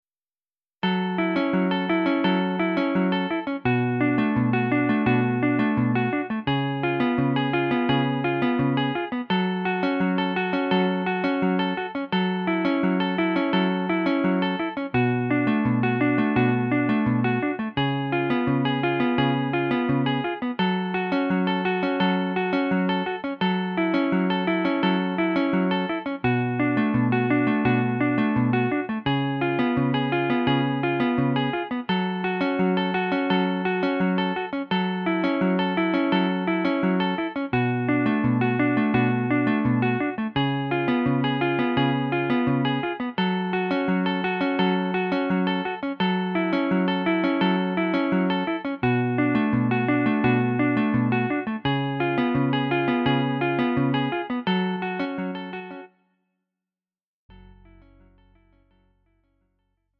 음정 -1키 3:26
장르 pop 구분 Lite MR